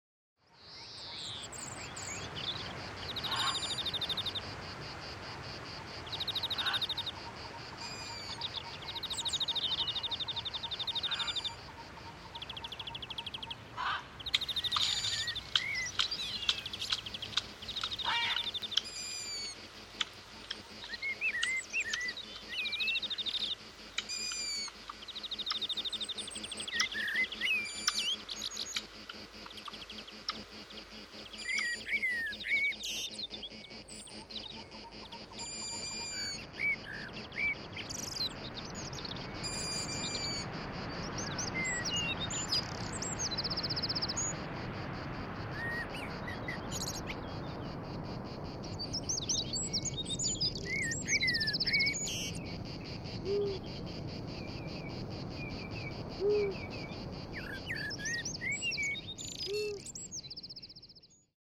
Wald, Wiese, Fluß, Meer , Land   33:00 min 0,99 EUR